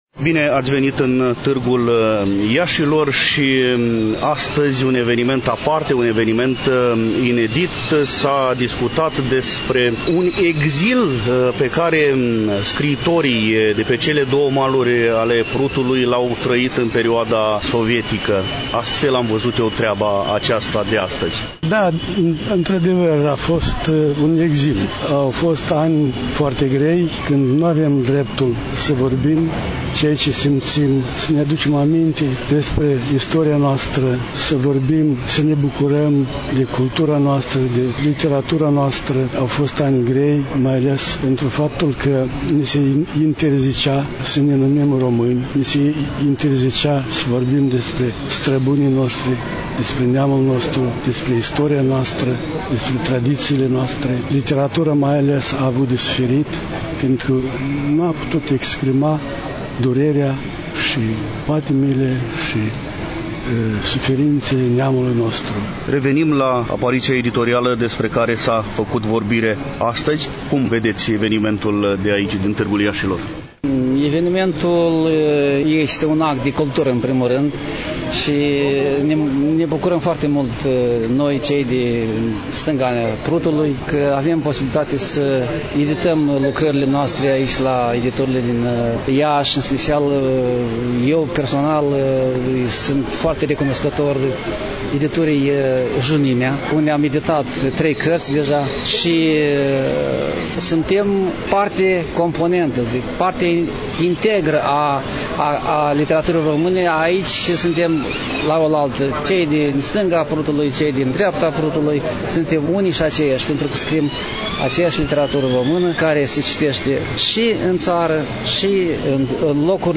În emisiunea de astăzi, după cum bine știți… relatăm de la Târgul de carte LIBREX 2023, manifestare desfășurată, la Iași, în incinta Palas Mall, în perioada 10 – 14 mai.